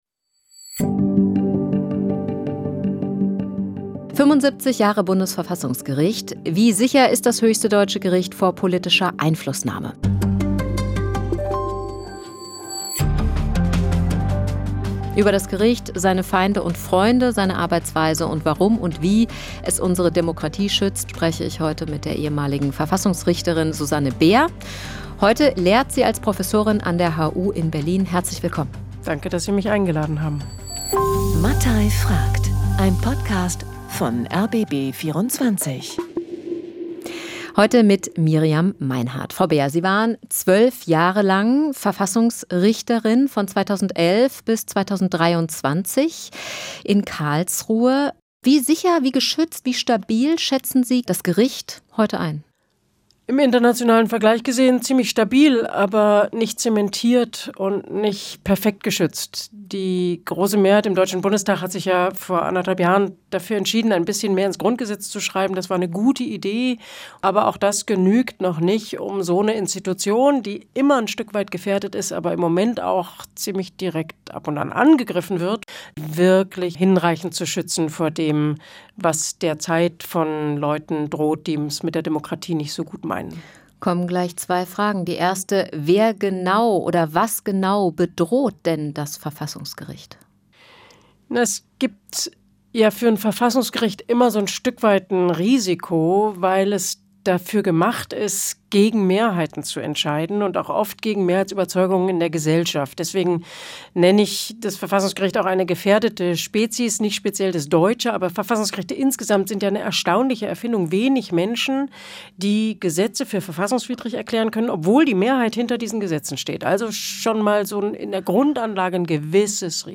Ein Gast, eine These, jede Menge Fragen.